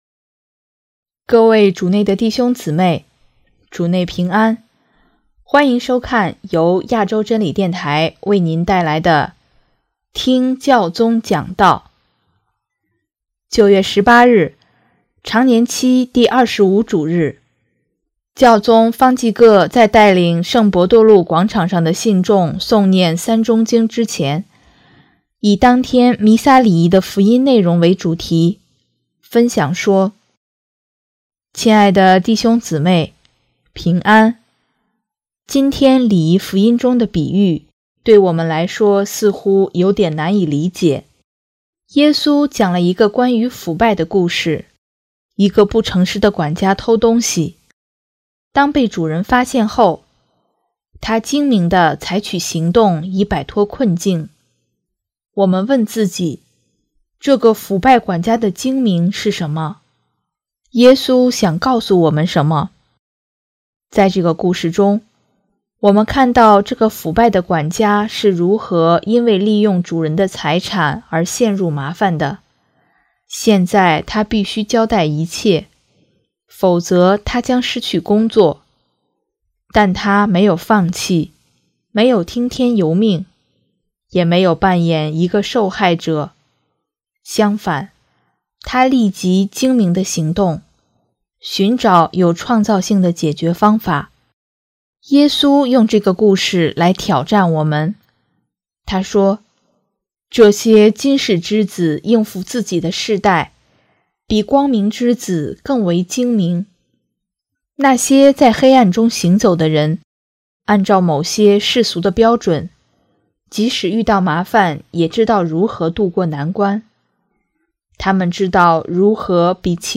9月18日，常年期第二十五主日，教宗方济各在带领圣伯多禄广场上的信众诵念《三钟经》之前，以当天弥撒礼仪的福音内容为主题，分享说：